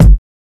Wu-RZA-Kick 57.wav